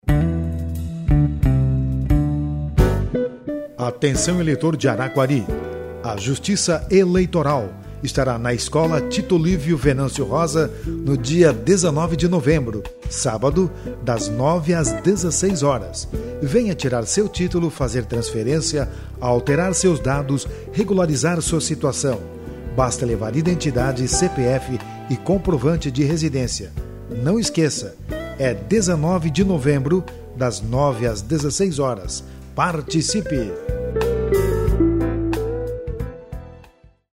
spot_araquari.mp3